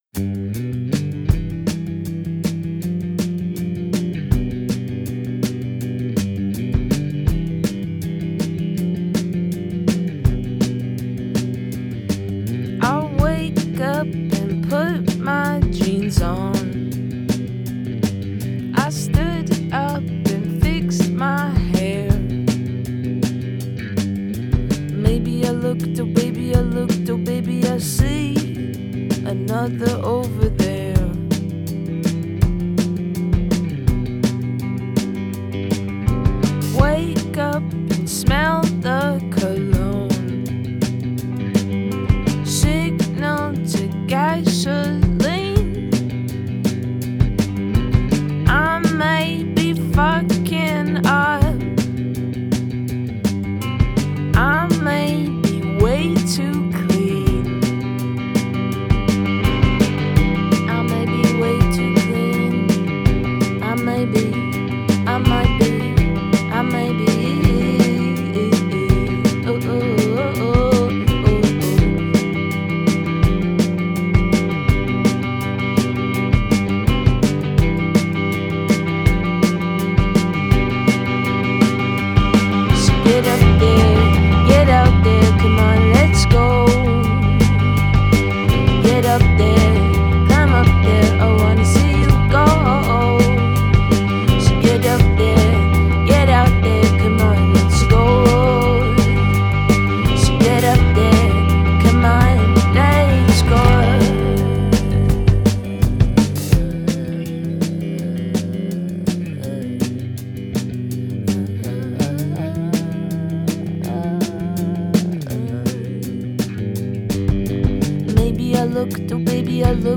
pop-punk group